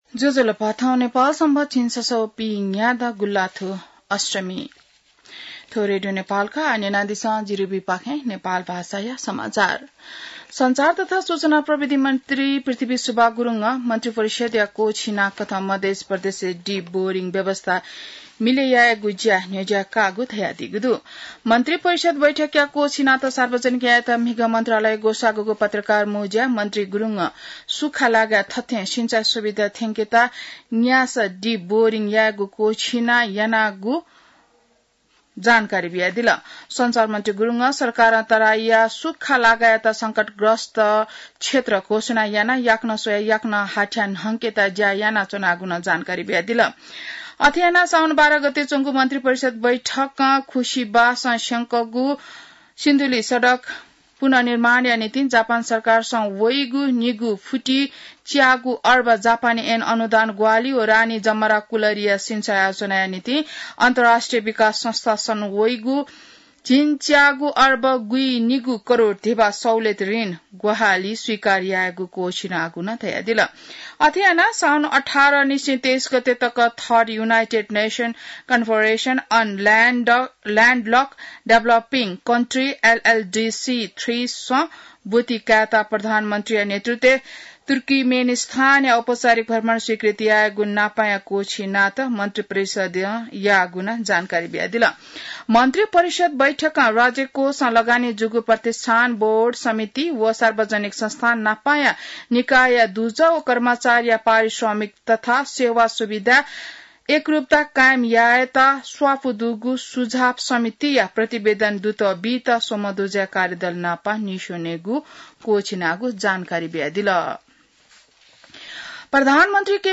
नेपाल भाषामा समाचार : १६ साउन , २०८२